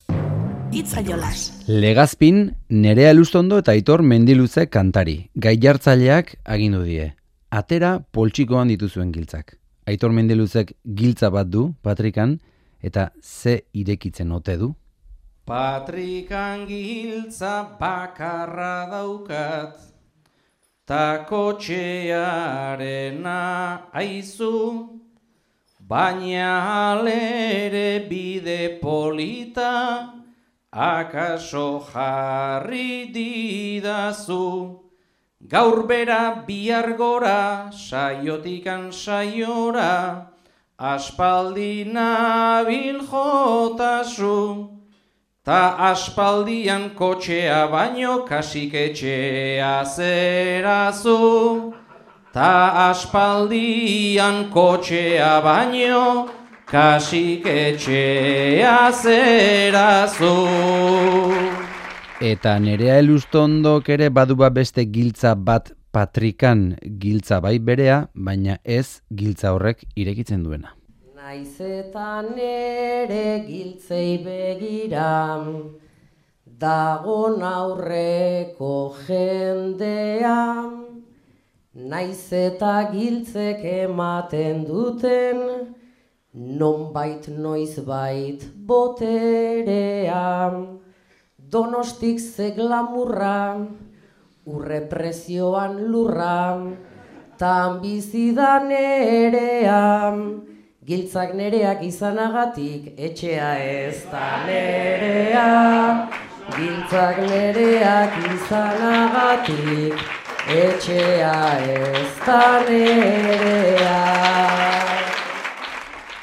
bertsoaldia